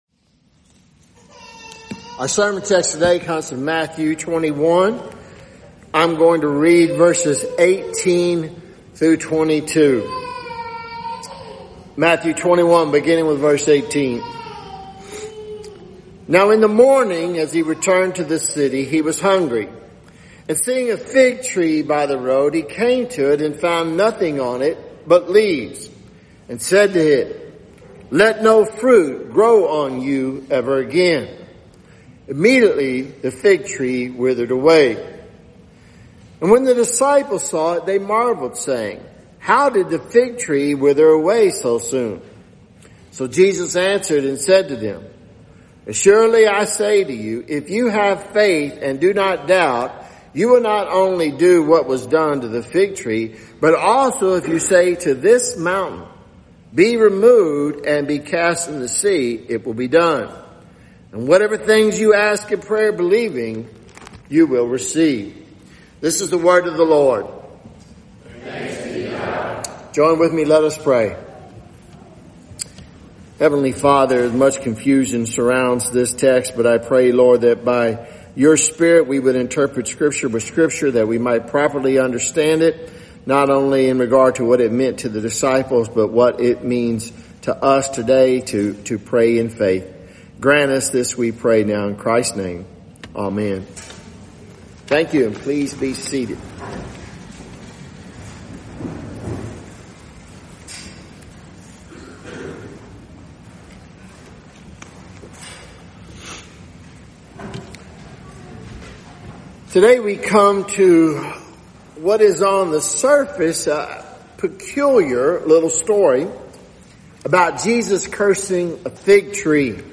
Sermon and Sunday school recordings – Christ Covenant Presbyterian Church